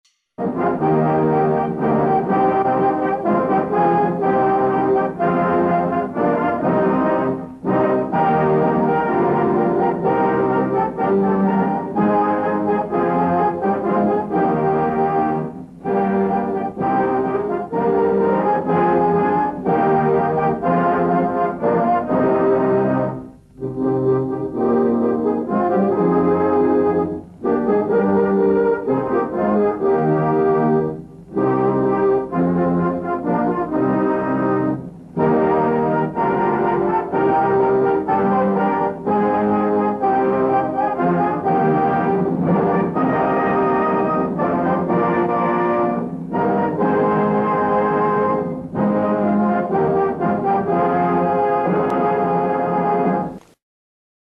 National-Anthem-JanaGanaMana-Orchestra.mp3